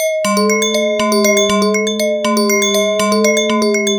Glass FM.wav